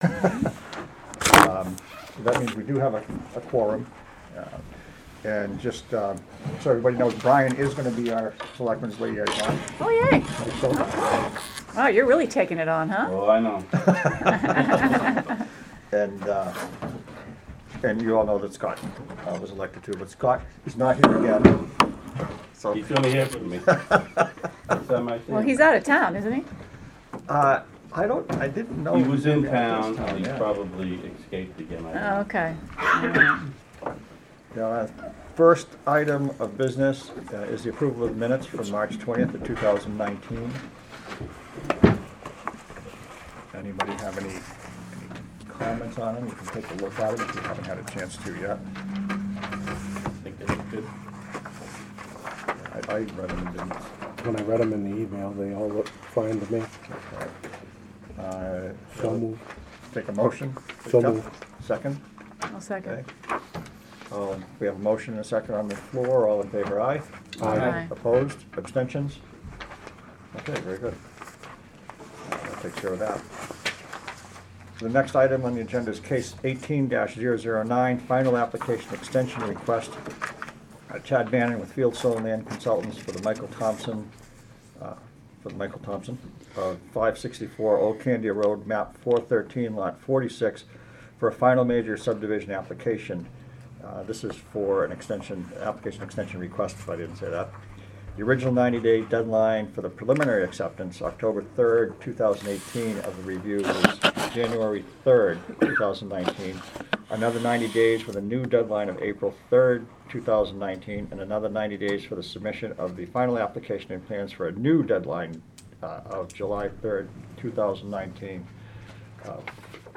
Audio recordings of committee and board meetings.
Planning Board Meeting